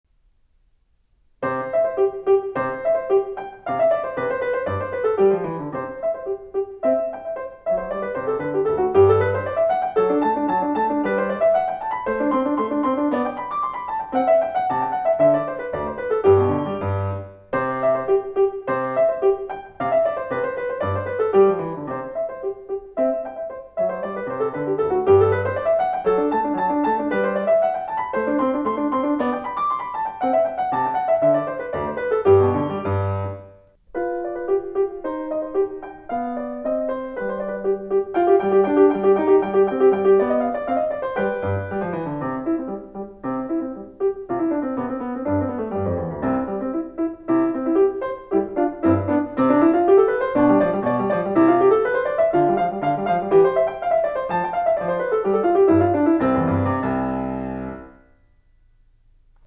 AABA -